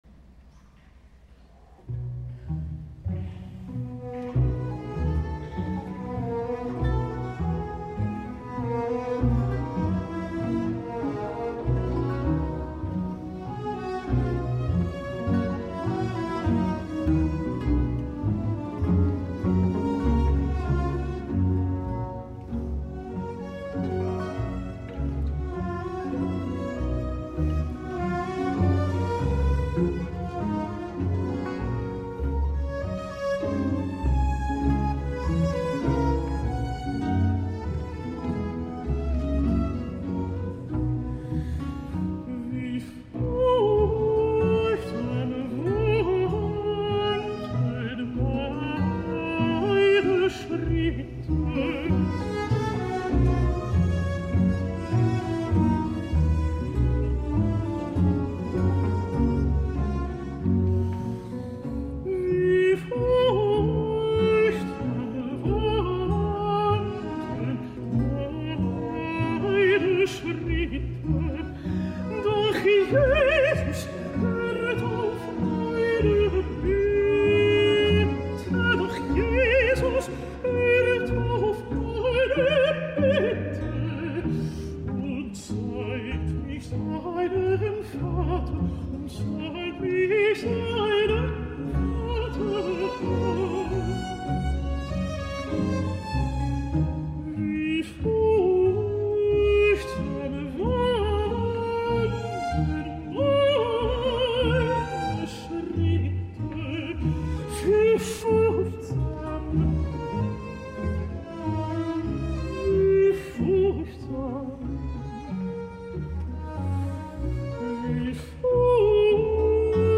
Us deixo amb Johan Sebastian Bach interpretat per Orfeo55 i la contralt Nathalie Stutzmann que també fa de directora, cantant l’ària “Wie furchtsam wankten” de la Cantata Allein zu dir, Herr Jesu Christ, BWV 33, en una interpretació efectuada el passat 6 d’octubre al Festival d’Ambronay